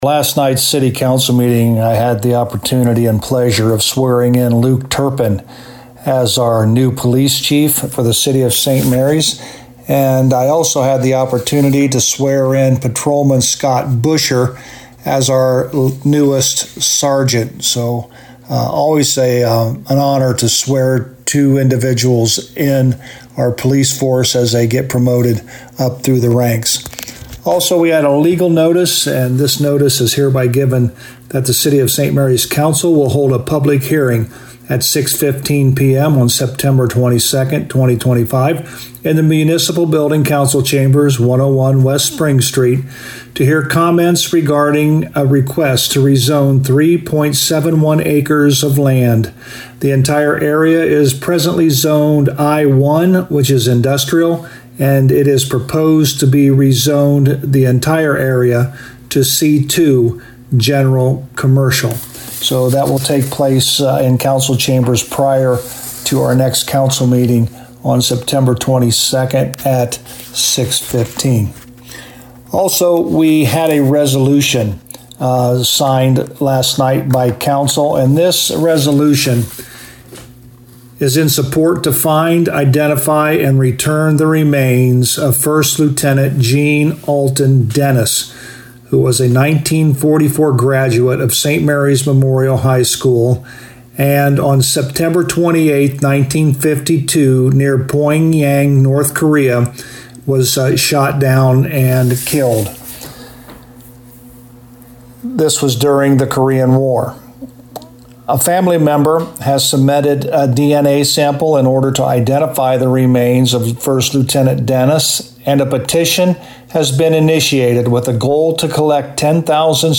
To hear the summary with St Marys Mayor Joe Hurlburt: